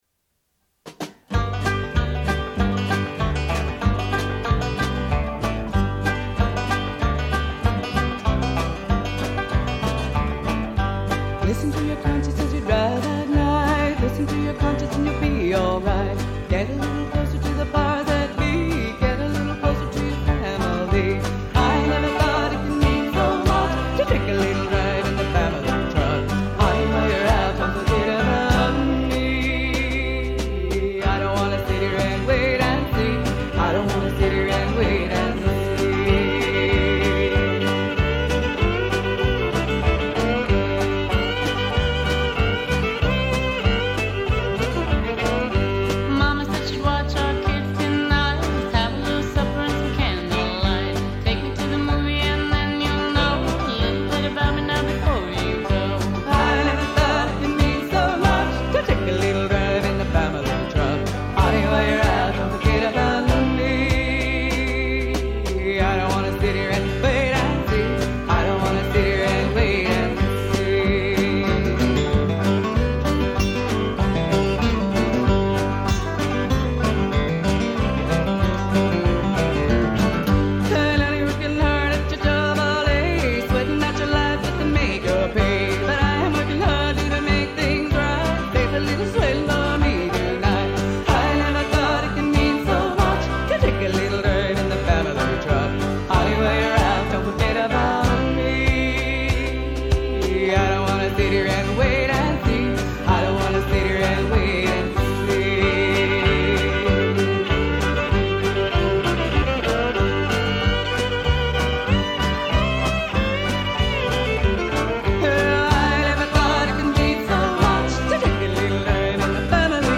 banjo
acoustic guitar
electric guitar
fiddle
electric bass
drums